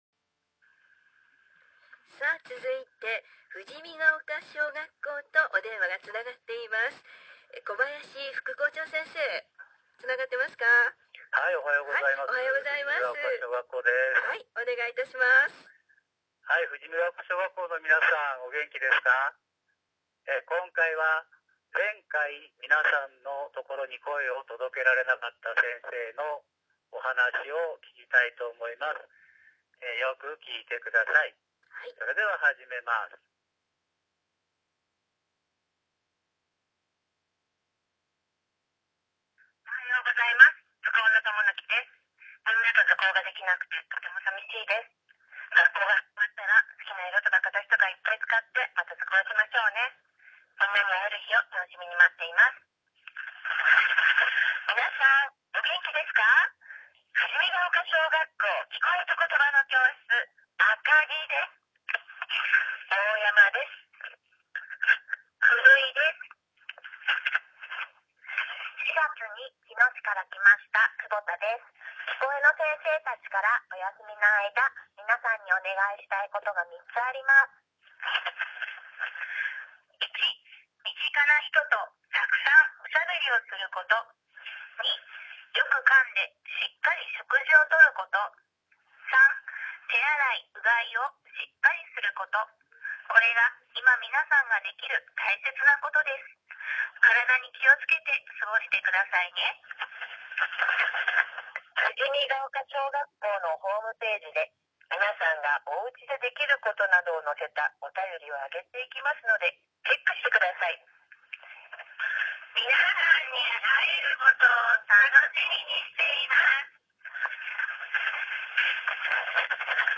今回は、さくら学級やきこえと言葉の教室の先生方を中心に、あらかじめ録音しておいたメッセージを送りました〈左〉。 締めの言葉は、会議中の職員室から「富士見丘小学校　絶好調～！！」でした〈右〉。